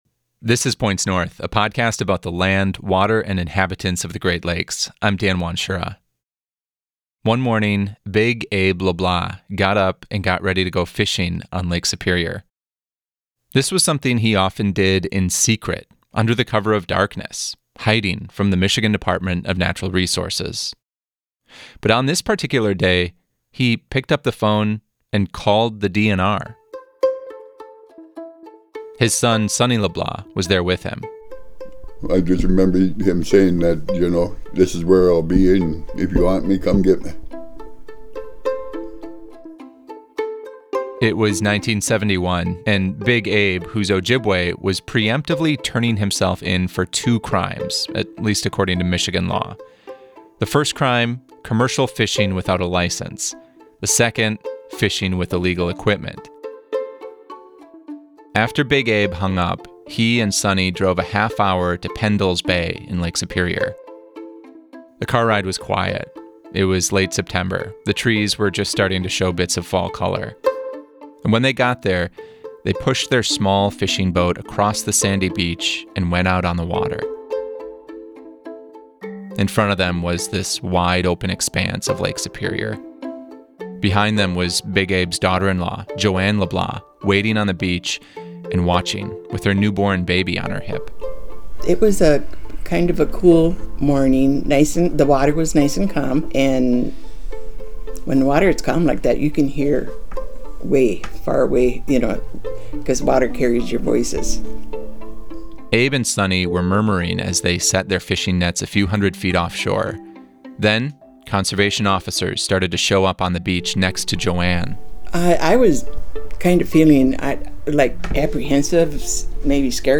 Points North is an award-winning podcast about the land, water, and inhabitants of the Great Lakes. Through narrative, sound-rich journalism that is deeply rooted in a sense of place, each episode entertains, informs, and surprises listeners everywhere.